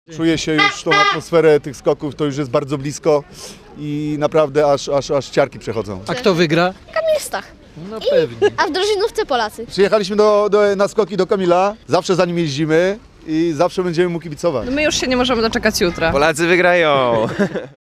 skoki kibice.mp3